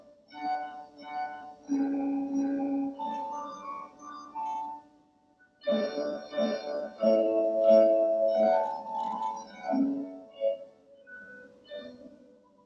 一方中間部は、丁寧なテヌート（黄）に滑らかなスラー（青）が絡み、アクセントにスタッカート（赤）が映えています。
gossec-gavotte-mid.m4a